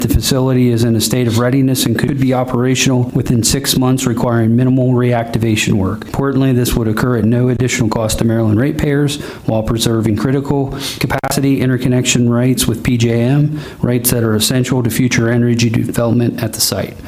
Bennett read a portion of the letter that said reopening would bring jobs and taxes back to the county…